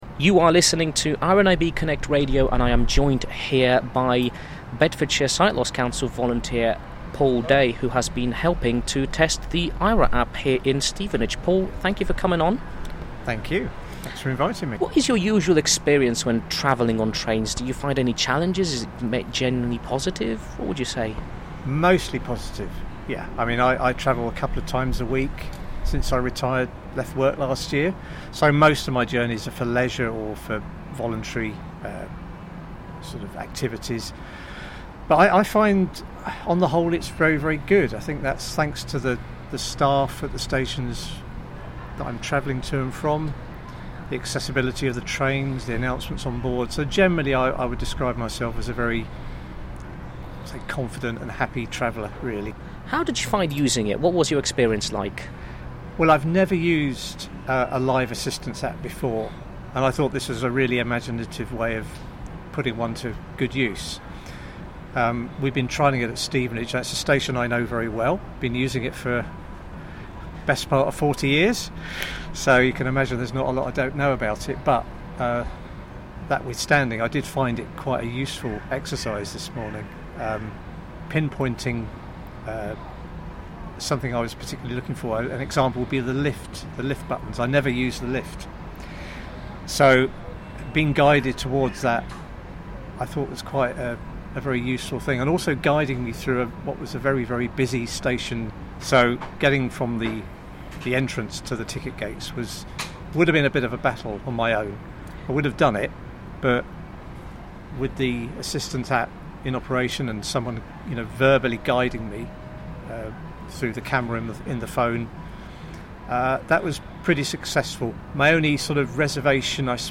he spoke afterwards to one of the volunteer testers about their experience using the app.